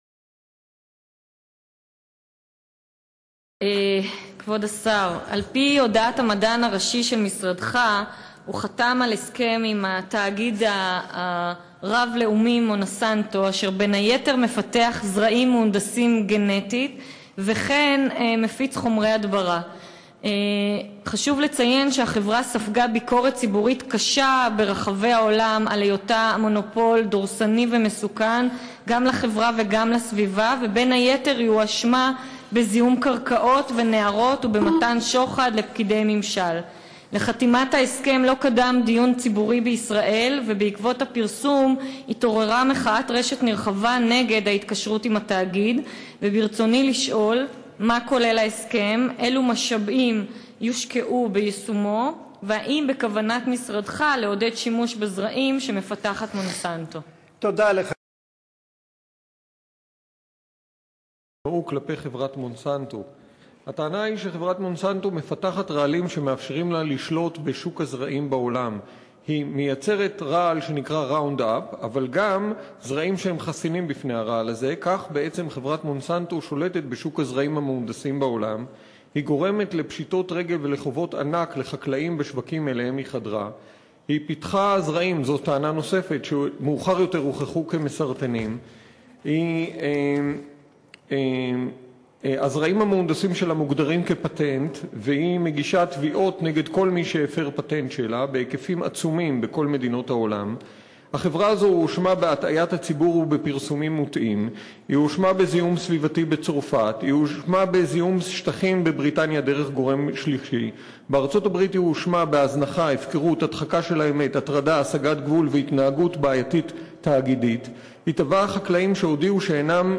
לפני כמה שנים חתם שר הכלכלה דאז נפתלי בנט על הסכם סטנדרטי כדבריו עם תאגיד מונסנטו. להזכירכם, מדובר בחברה שעוסקת בהנדסה גנטית של זרעים וייצור חומרי הדברה. תראו איך הוא עושה הכל כדי לא לענות על השאלות שהוא נשאל במליאת הכנסת.